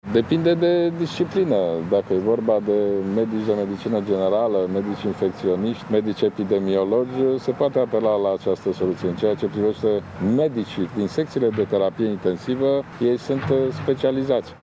Premierul Ludovic Orban a declarat că acest lucru fa vi dificil de făcut în cazul medicilor de la Terapie Intensivă, pentru că acolo e vorba de medici specializaţi şi nu pot fi înlocuiţi cu uşurinţă.